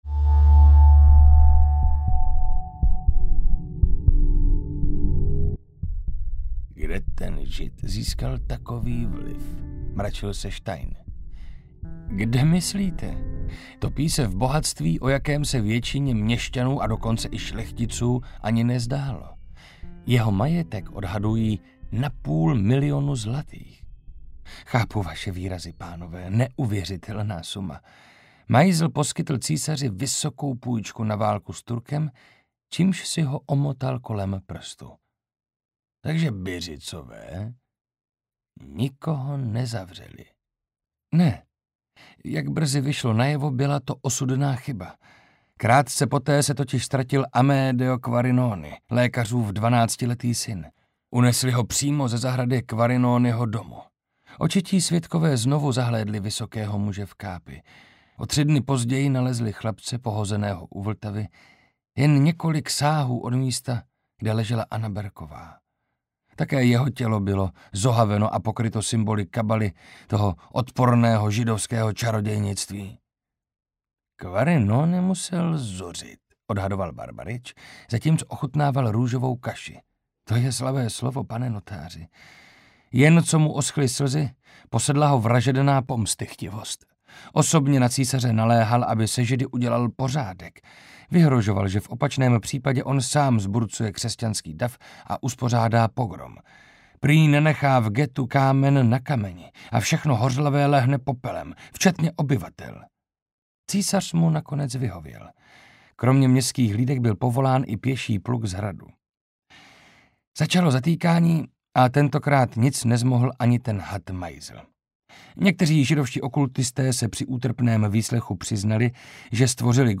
Audiobook
Read: Marek Holý